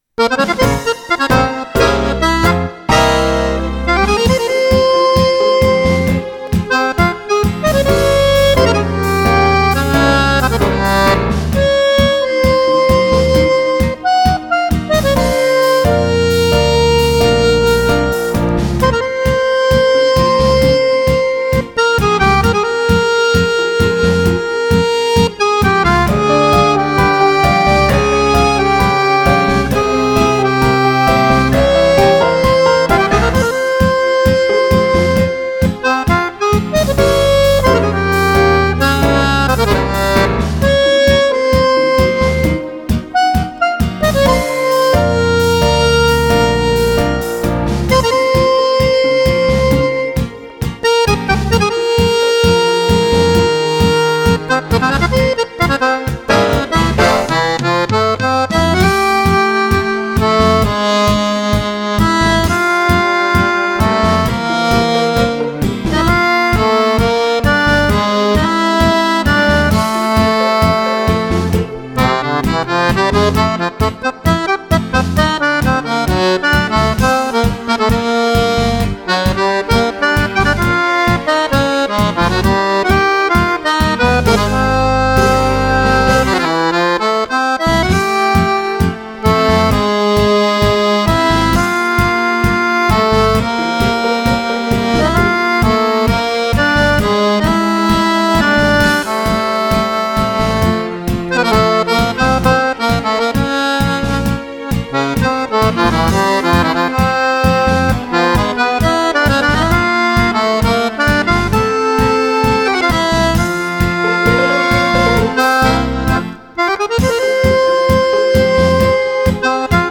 8° FESTIVAL NAZIONALE BRANI INEDITI PER FISARMONICA
Tango Argentino